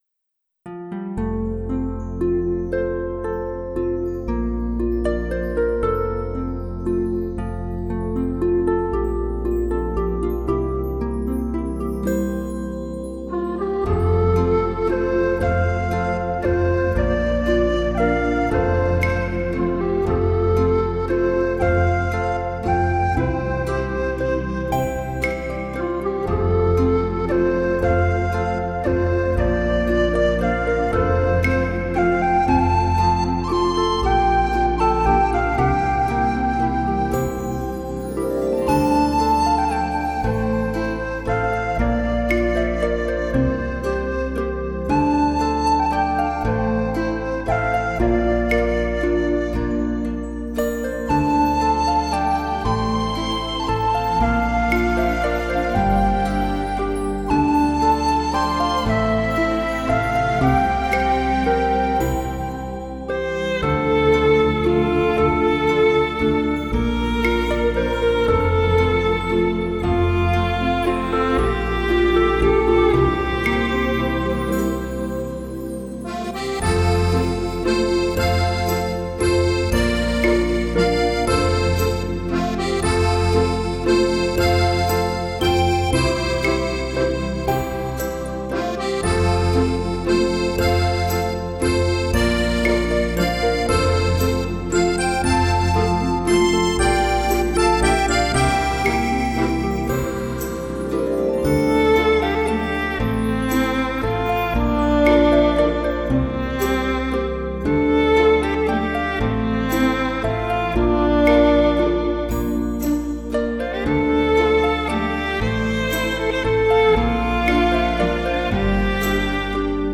丰富的韵律，动人的心弦，堪称经典，让人们在新世纪音乐花园里，去感受优美、经典的世界音乐风情。